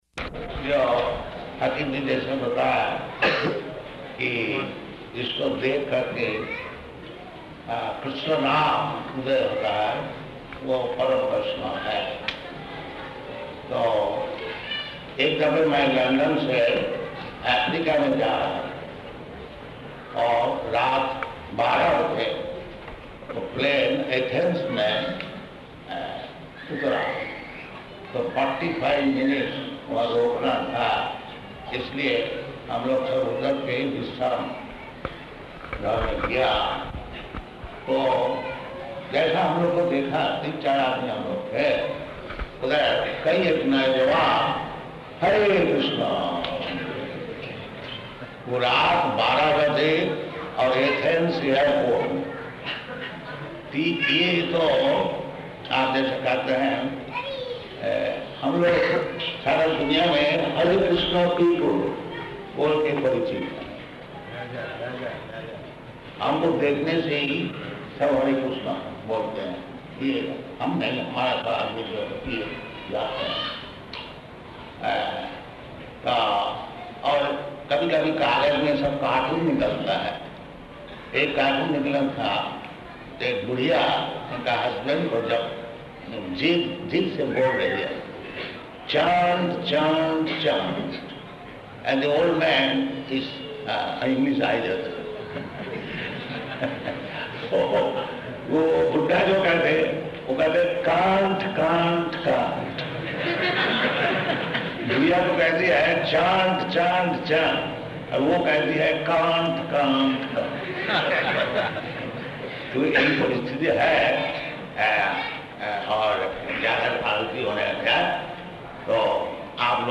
Speech in Hindi [partially recorded]
Location: Vṛndāvana